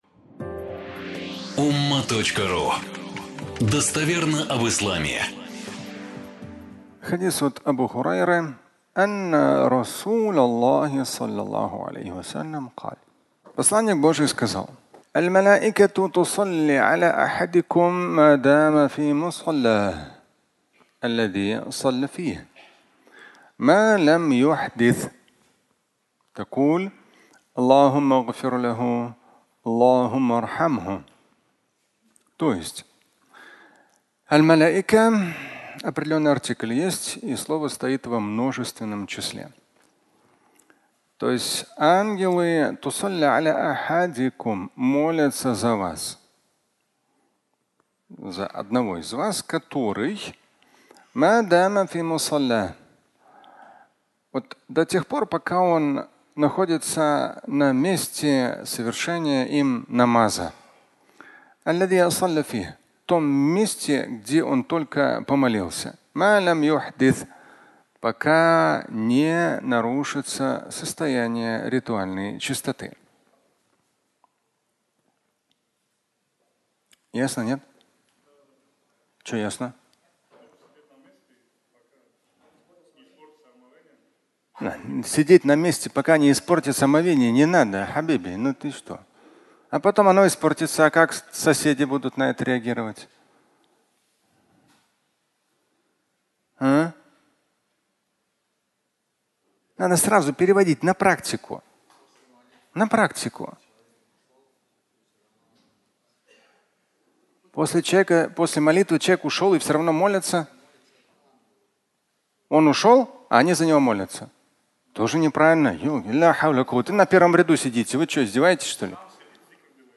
Ангел молится (аудиолекция)